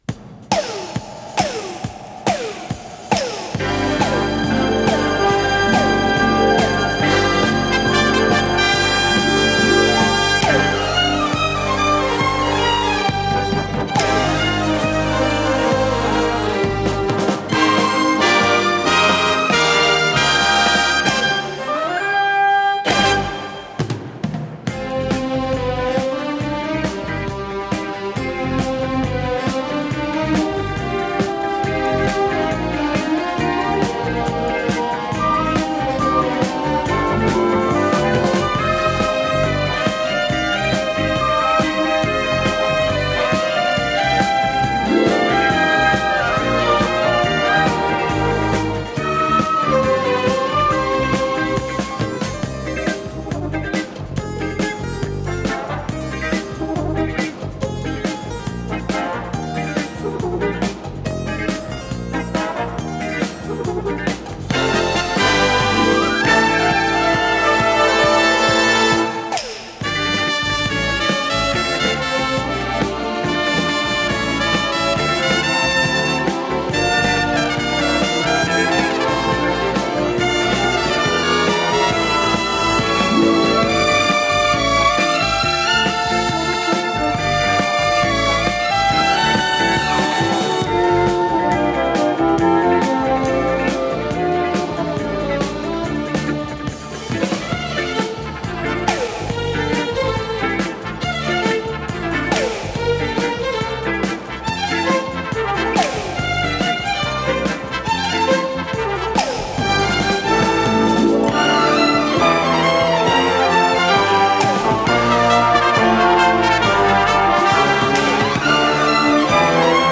с профессиональной магнитной ленты
ПодзаголовокОркестровая пьеса
ВариантДубль моно